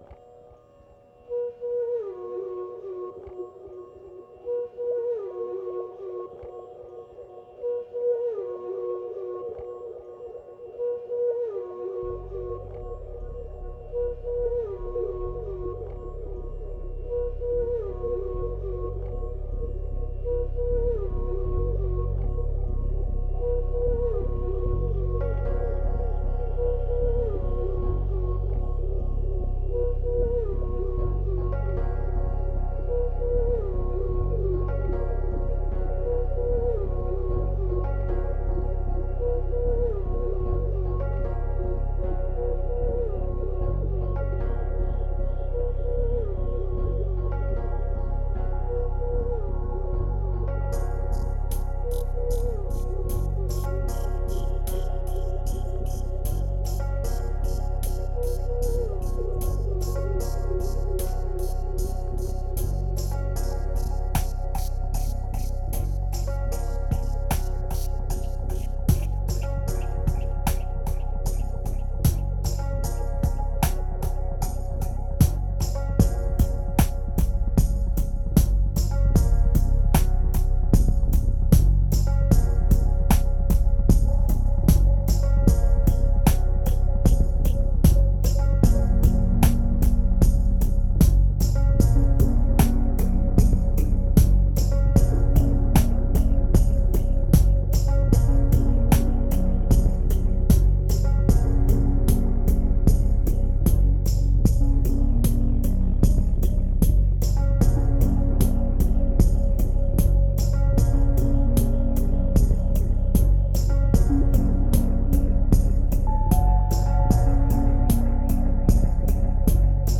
2394📈 - 38%🤔 - 76BPM🔊 - 2011-01-06📅 - -23🌟